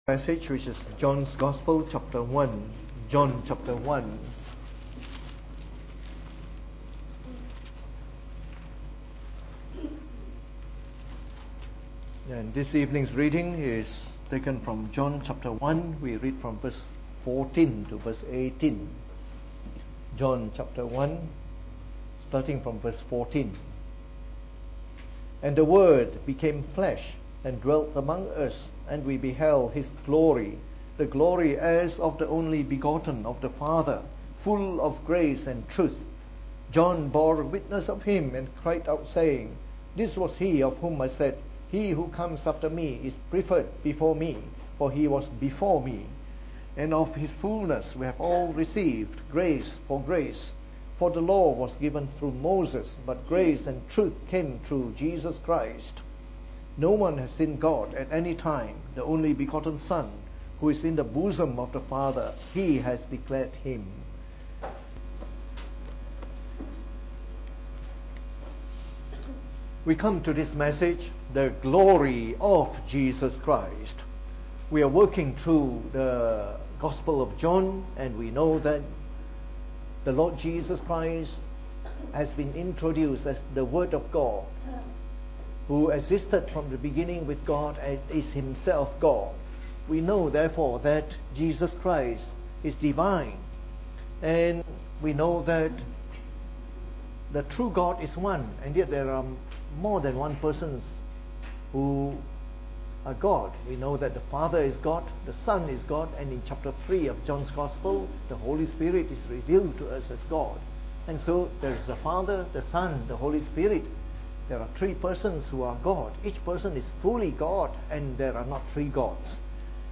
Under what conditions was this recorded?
Preached on the 8th July 2018. From our series on the Gospel of John delivered in the Evening Service.